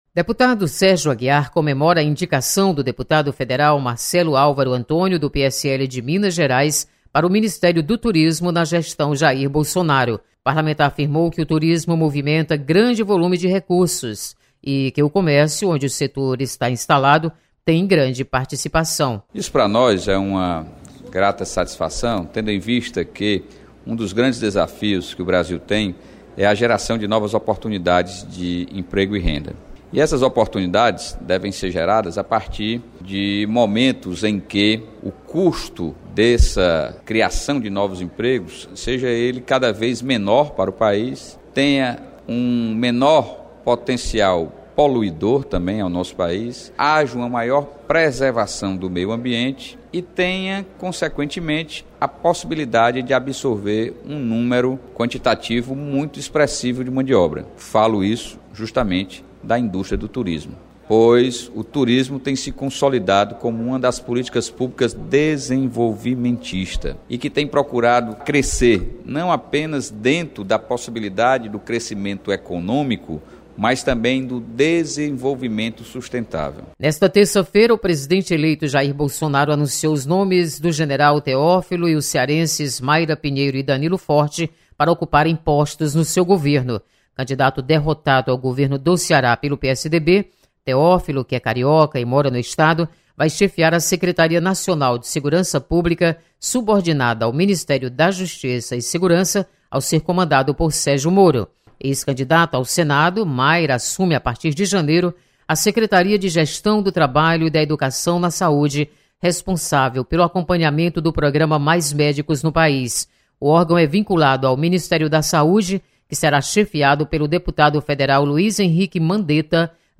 Deputado Sérgio Aguiar comemora indicação de futuro ministro do Turismo. Repórter